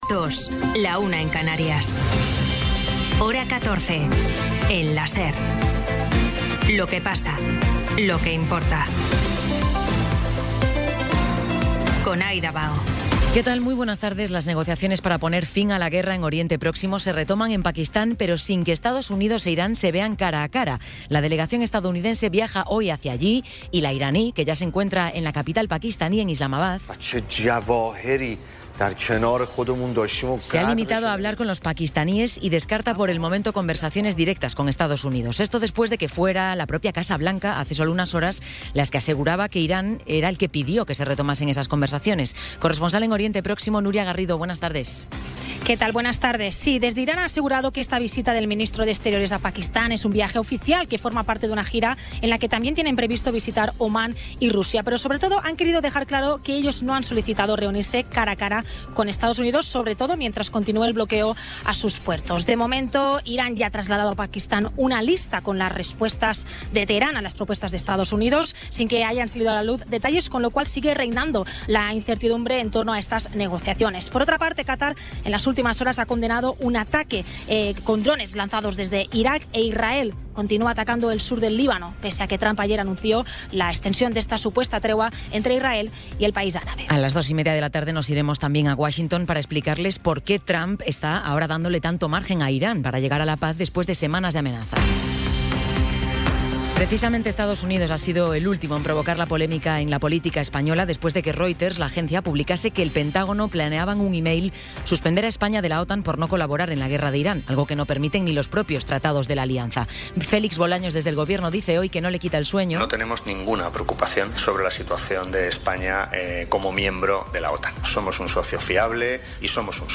Resumen informativo con las noticias más destacadas del 25 de abril de 2026 a las dos de la tarde.